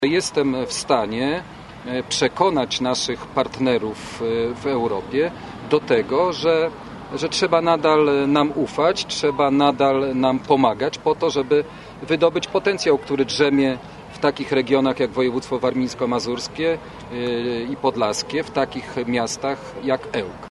„Mam wiedzę i doświadczenie, które pozwoli mi skutecznie reprezentować nasz region w Parlamencie Europejskim”, mówił dziś (23.05.19) na konferencji prasowej w Ełku były marszałek województwa warmińsko mazurskiego, obecnie poseł Jacek Protas.